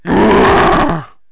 bull_attack4.wav